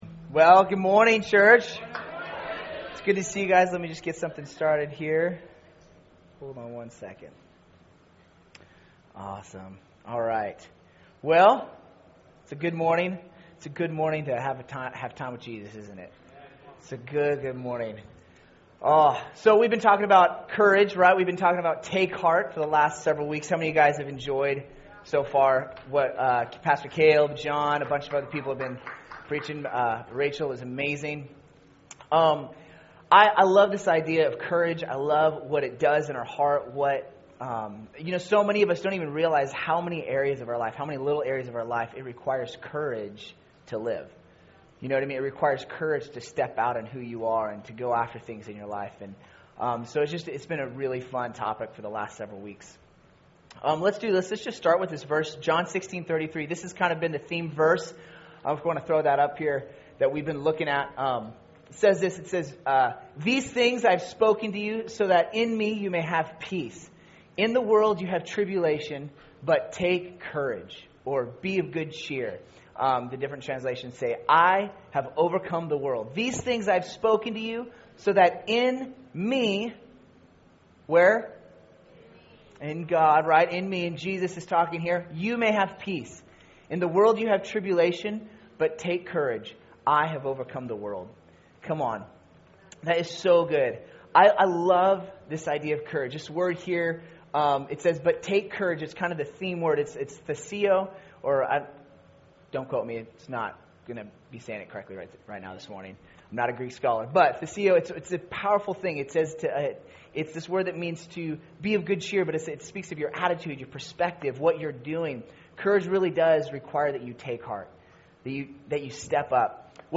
Recorded at New Life Christian Center, Sunday, April 24, 2016 at 11 AM.